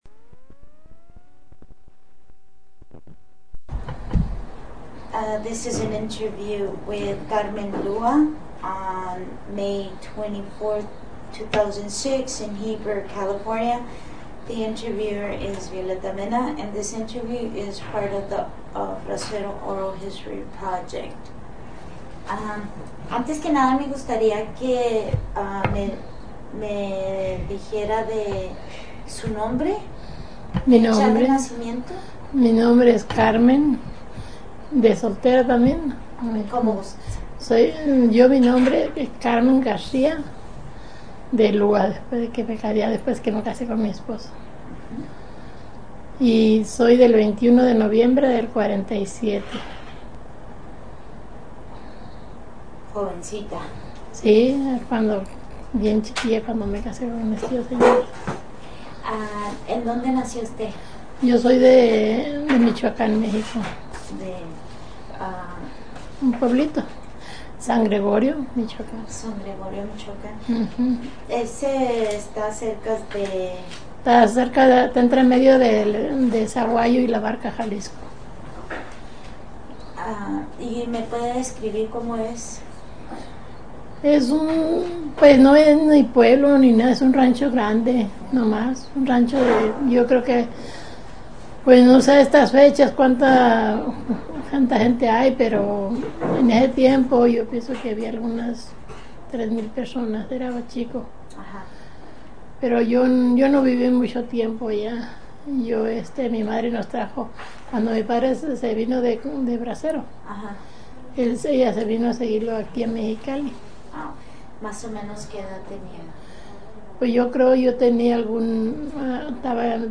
Original Format Mini DIsc